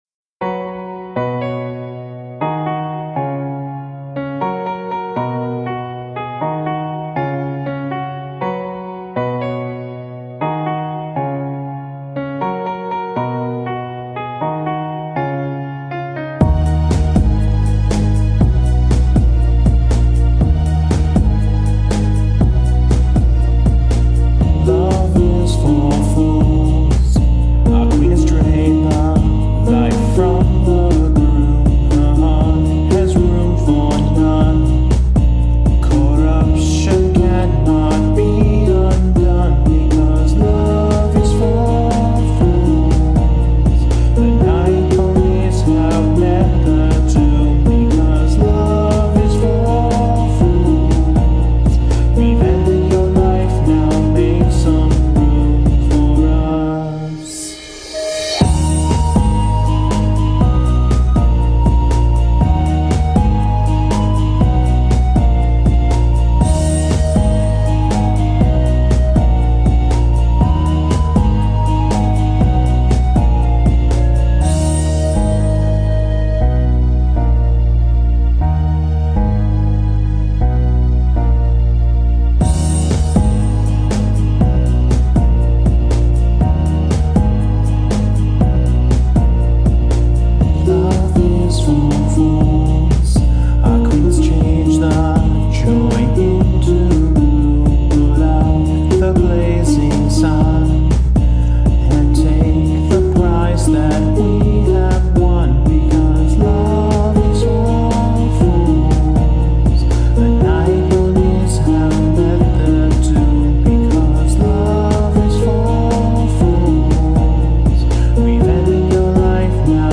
Unlike my last two songs, this one was made to be serious.